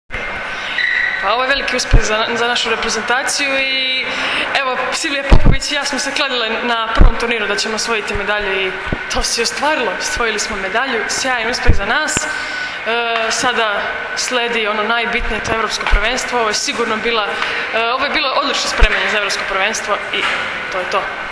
IZJAVA JOVANE VESOVIĆ